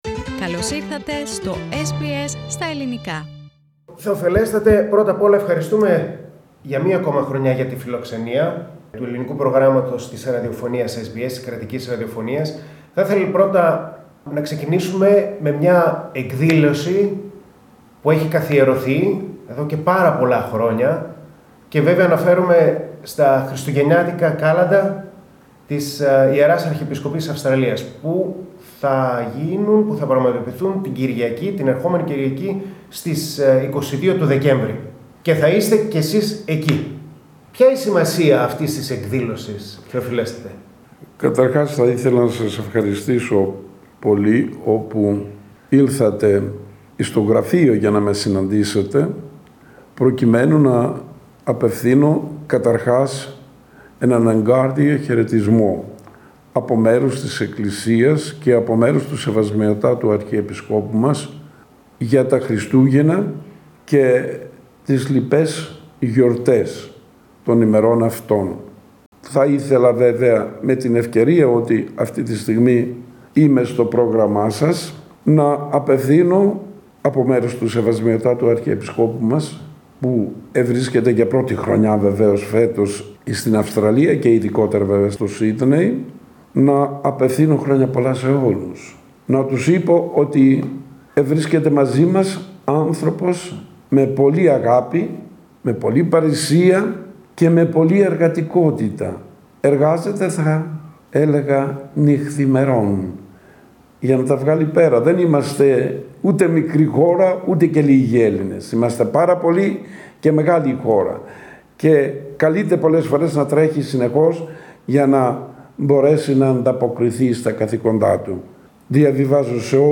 Για 36η συνεχή χρονιά, η Ιερά Αρχιεπισκοπή Αυστραλίας διοργανώνει τα Χριστουγεννιάτικα Κάλαντα με ύμνους, μελωδίες, τραγούδια και κάλαντα που παραπέμπουν στην μεγάλη αυτή γιορτή της Χριστιανοσύνης. Με αφορμή την εκδήλωση αυτή, ο Θεοφιλέστατος Επίσκοπος Δέρβης, κ. Ιεζεκιήλ μίλησε στο Πρόγραμμά μας, που βρέθηκε στα γραφεία της Αρχιεπισκοπής στο προάστιο South Melbourne.